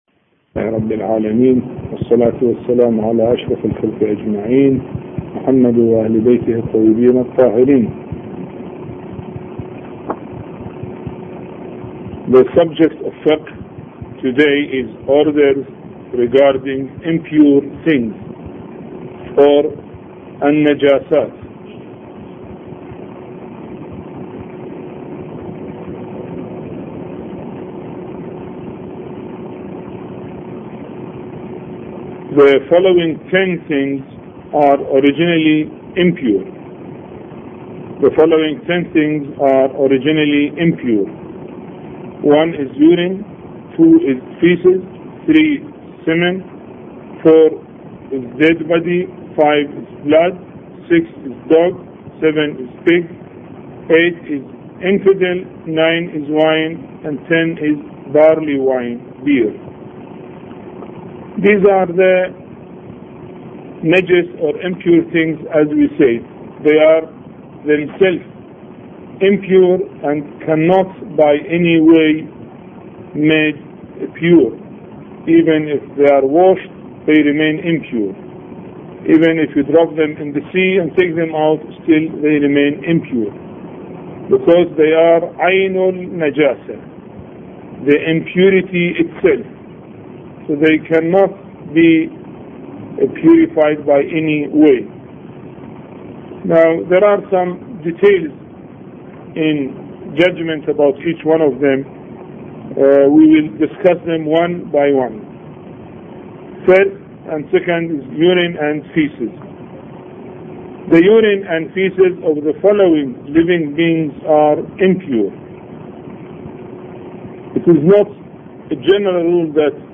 A Course on Fiqh Lecture 3